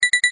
3beeps.wav